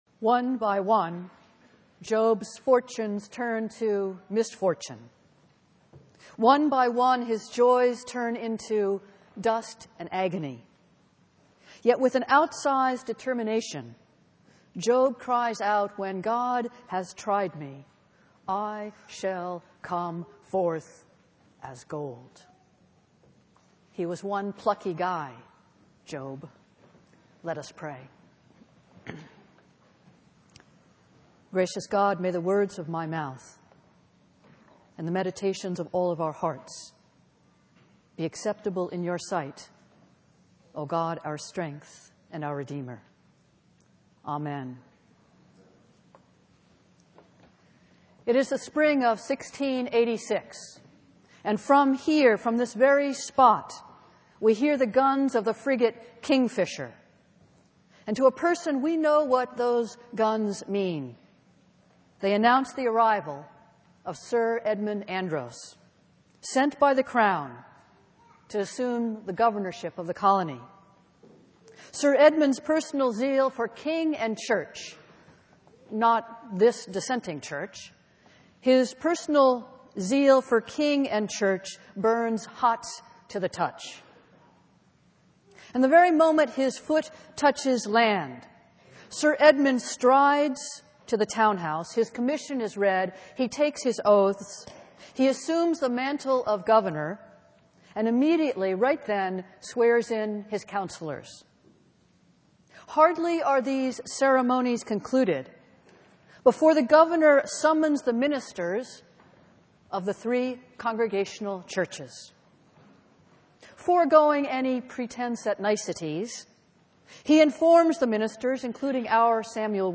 Festival Worship - Meeting House Sunday